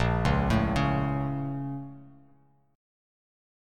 BbmM7 chord